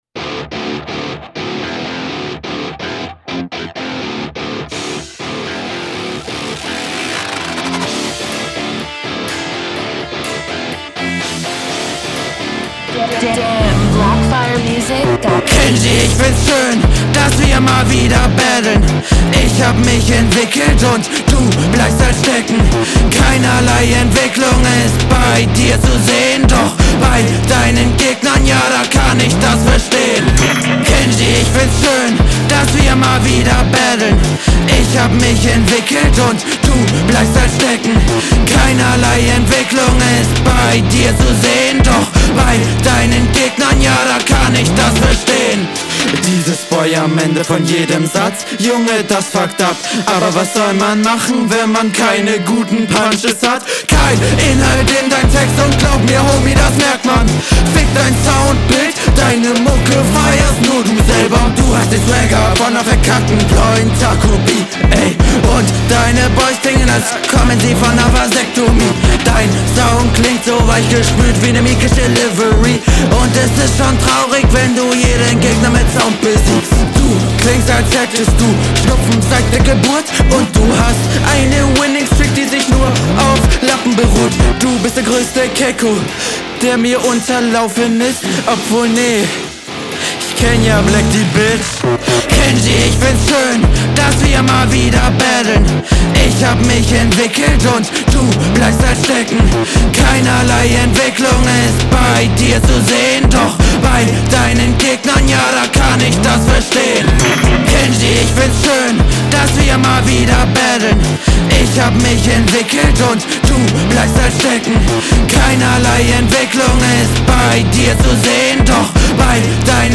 finde den stimmeinsatz leider echt nicht angenehm was für mich den gesamteindruck/hörgenuss und den flow …
Oh Gott, der Stimmeinsatz war ja mal ein Schuss in den Ofen.